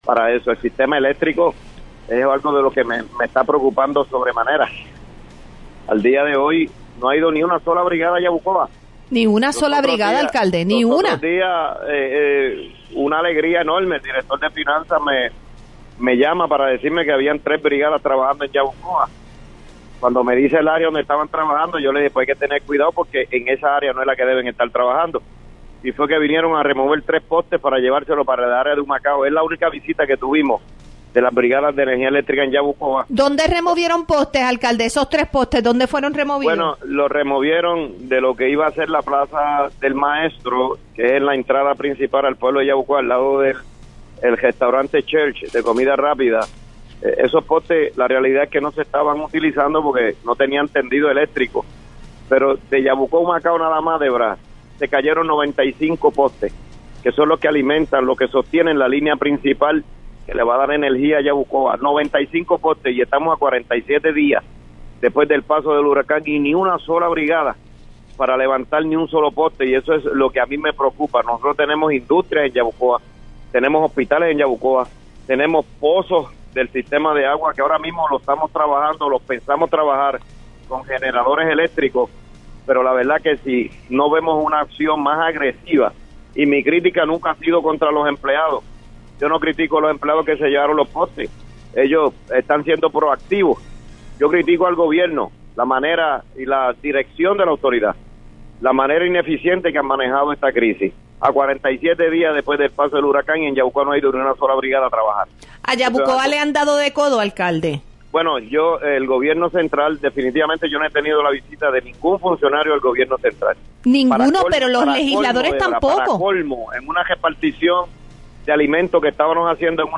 En entrevista con Para el Récord, el Alcalde también explicó que actualmente el Centro de Diagnóstico y Tratamiento (CDT) no ha recibido los fondos legislativos que le fueron asignados, pese a múltiples reuniones con la Cámara de Representantes y el Senado de Puerto Rico.